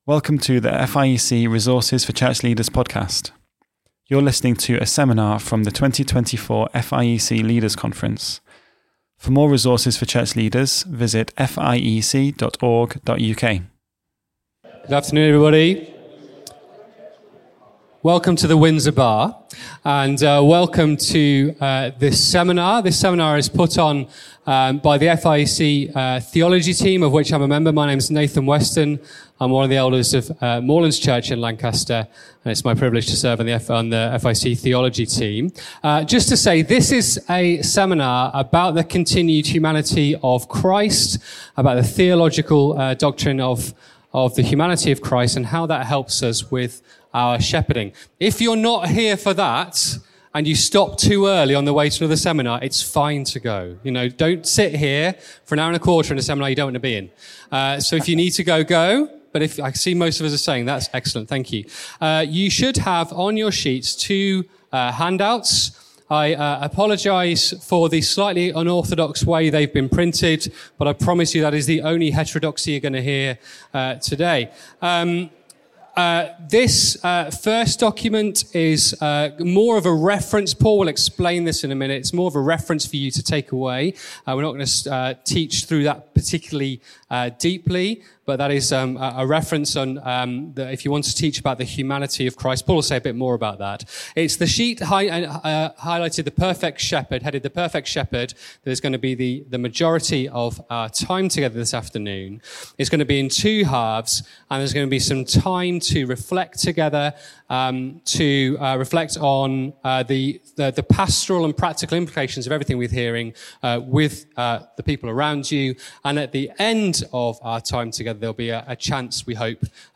How do the temptations Christ faced make him able to help us now and minister wisely and carefully to the flock? From the 2024 Leaders' Conference.